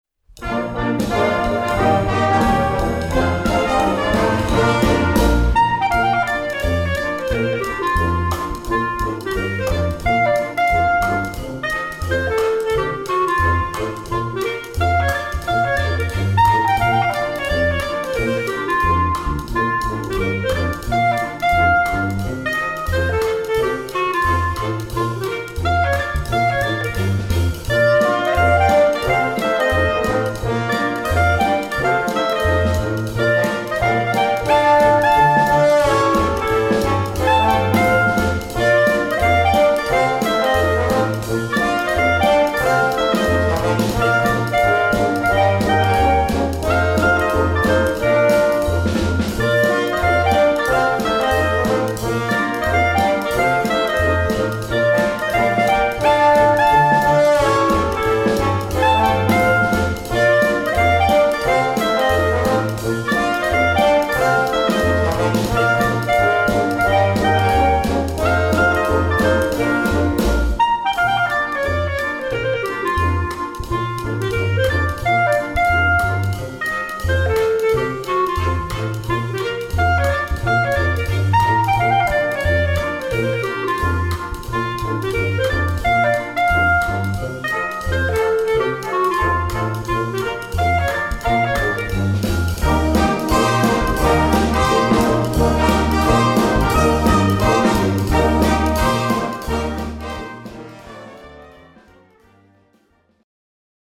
Gattung: Konzertstück mit Klarinetten Solo
Besetzung: Blasorchester